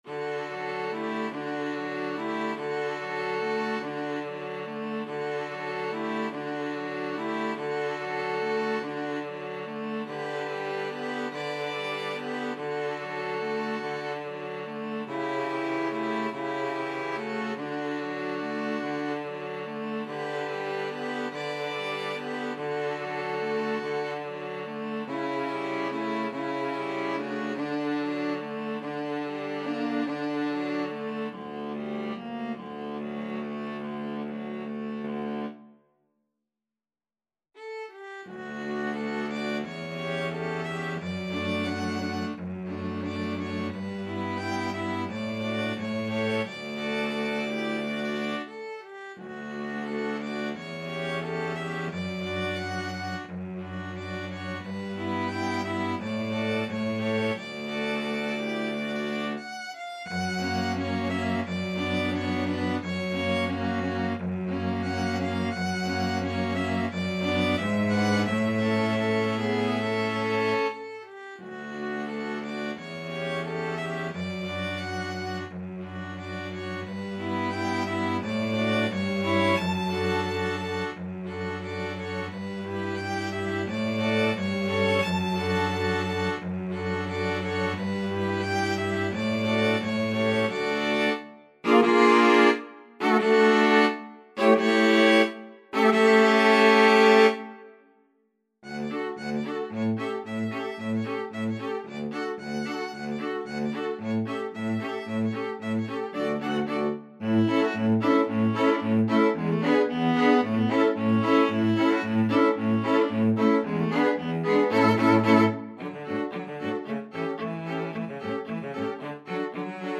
Violin 1Violin 2ViolaCello
3/4 (View more 3/4 Music)
Andante sostenuto (.=48)
Classical (View more Classical String Quartet Music)